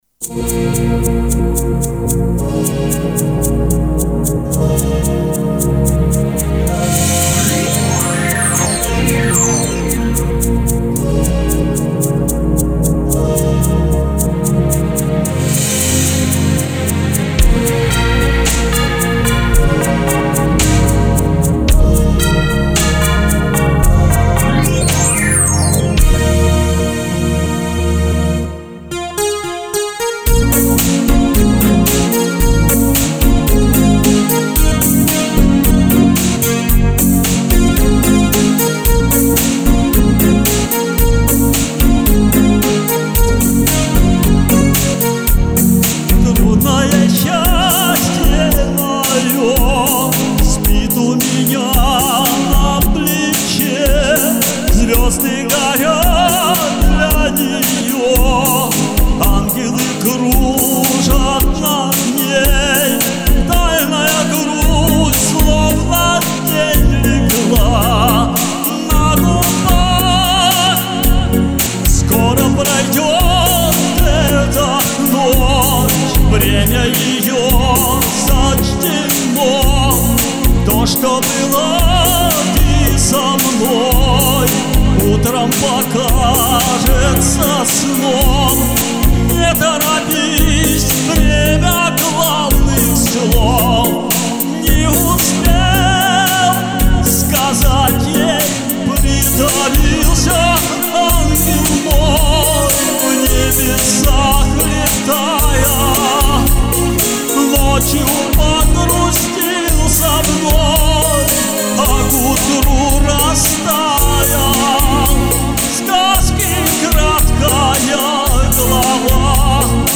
Эстрадное исполнение.